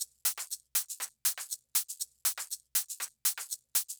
Drumloop 120bpm 07-B.wav